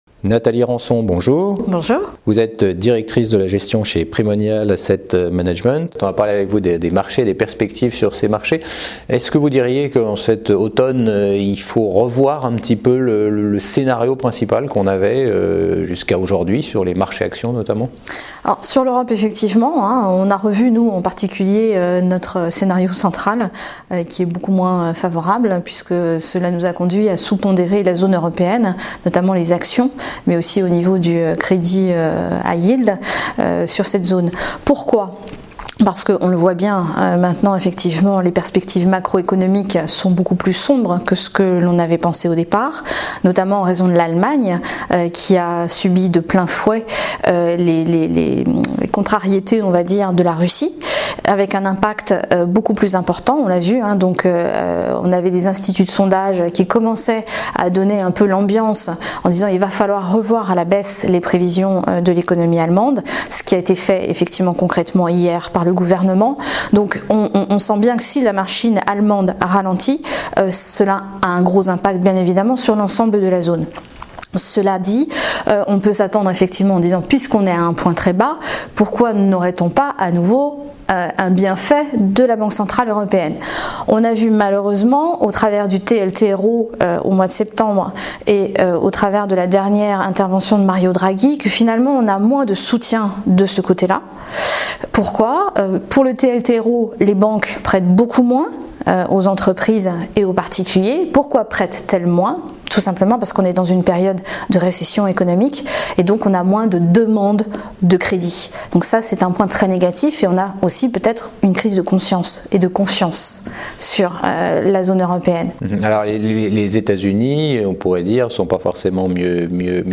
Cet interview a été tournée au Club Confair, 54 rue Laffite, 75009 Paris :